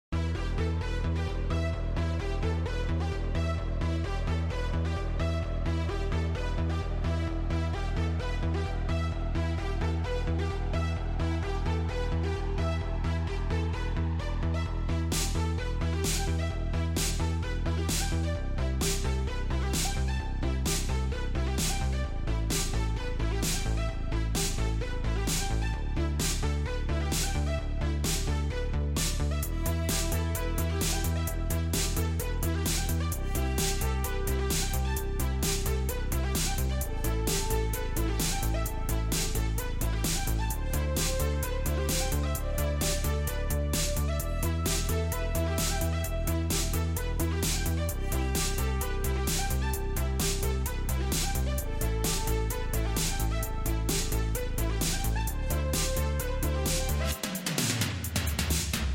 DJI Inspire 3 At Drones Sound Effects Free Download